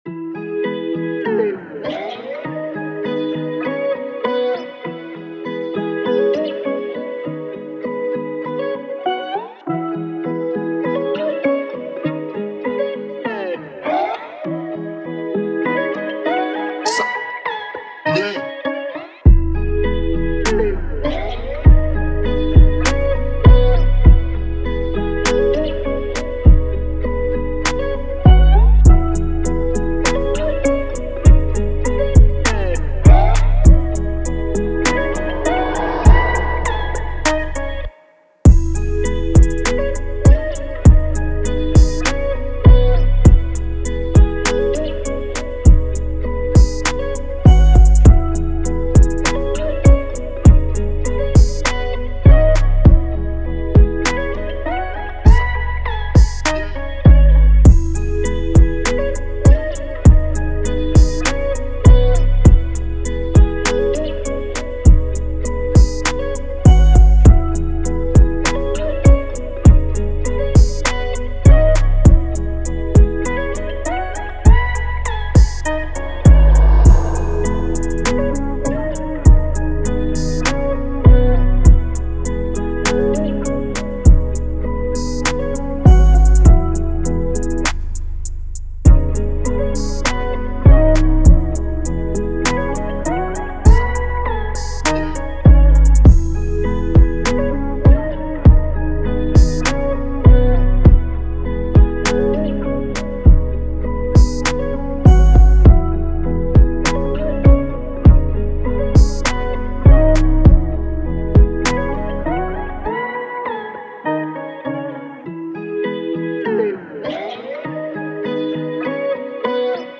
Positive, Chill, Vibe
Eletric Guitar, Lead, Piano, Strings